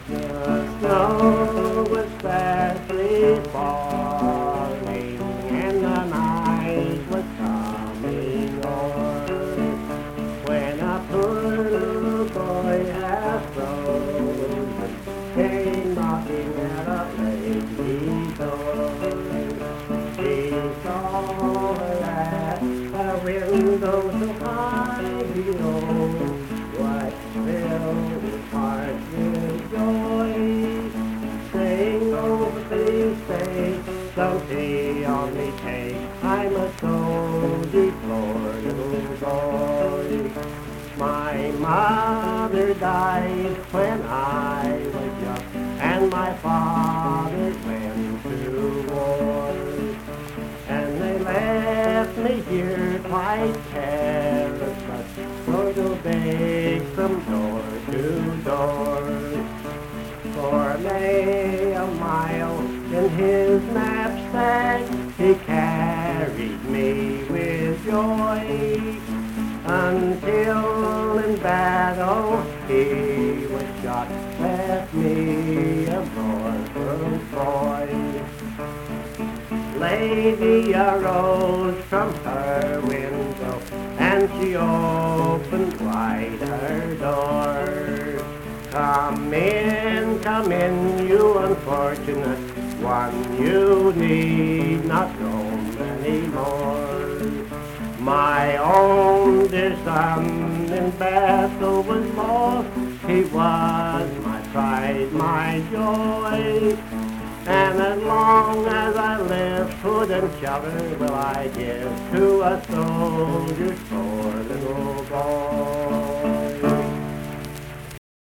Accompanied vocal and guitar music
Verse-refrain 3(8).
Performed in Hundred, Wetzel County, WV.
Voice (sung), Guitar